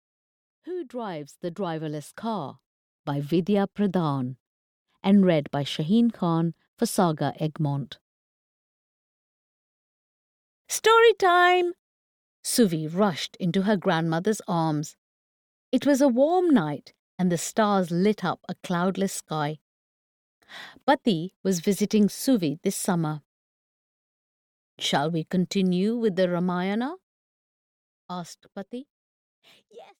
Who Drives the Driverless Car? (EN) audiokniha
Ukázka z knihy